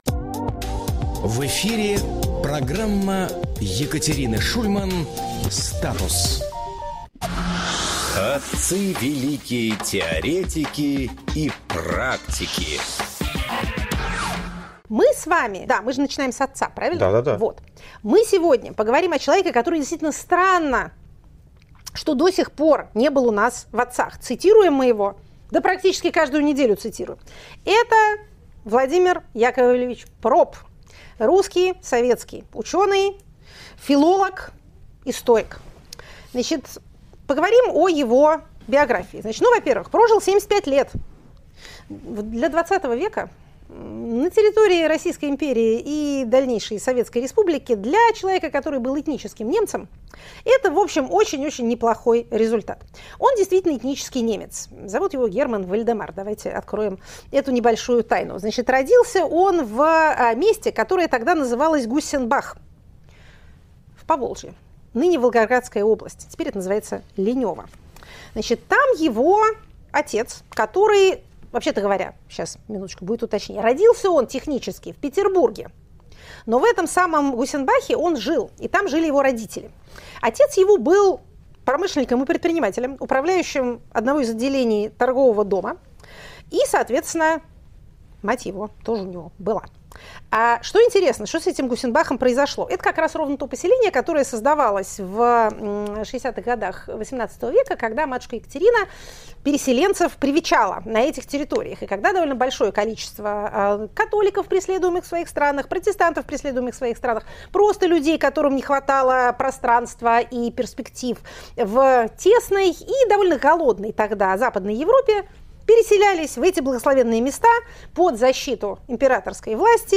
Эфир ведёт Максим Курников